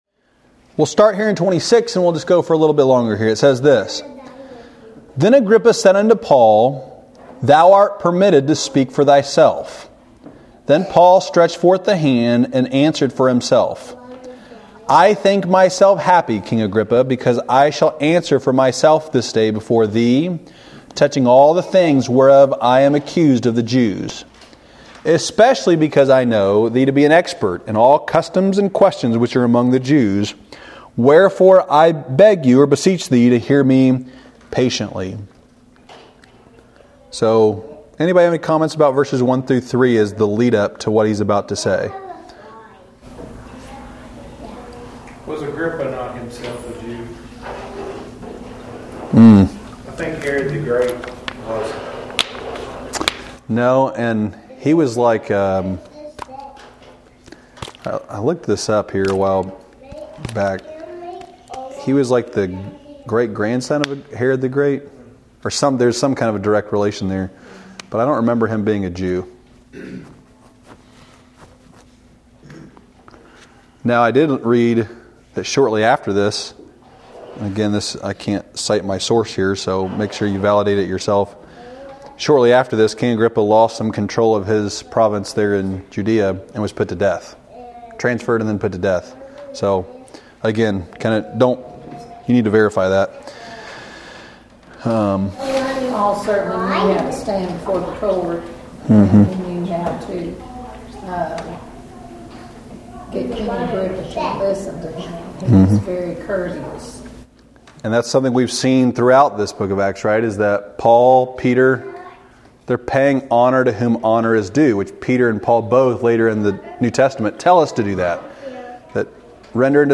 Below is the last six sermons from the pulpit.